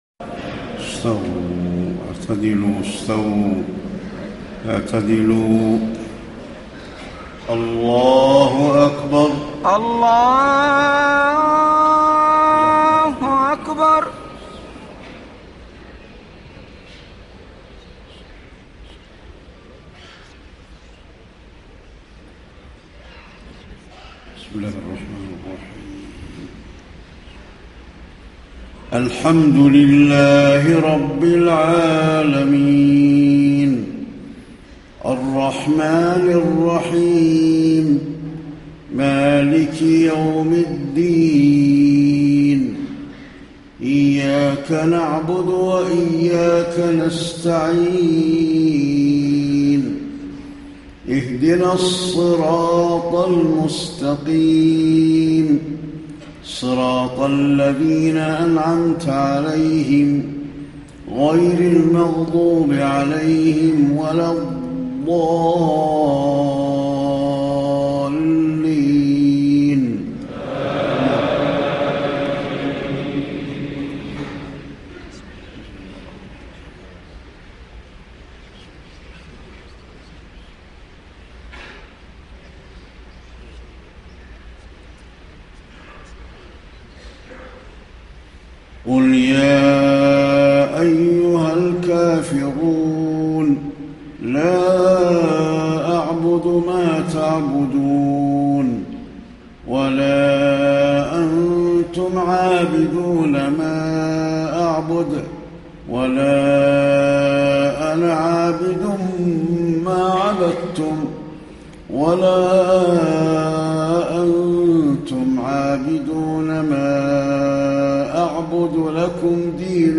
صلاة المغرب 7-5-1434 سورتي الفلق و الاخلاص > 1434 🕌 > الفروض - تلاوات الحرمين